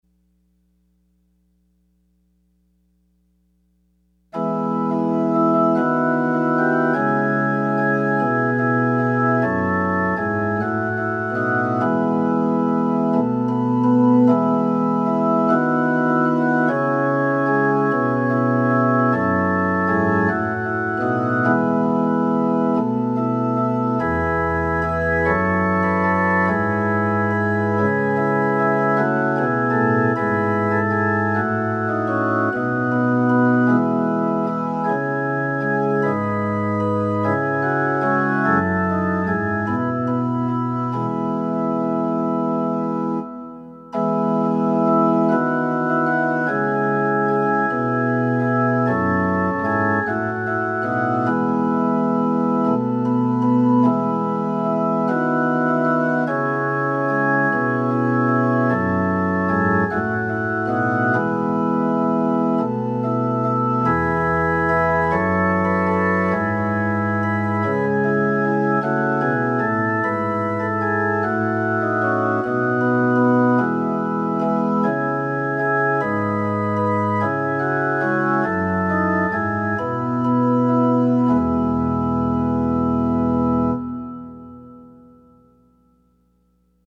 Offering Hymn  –  #679  Surely it is God who saves me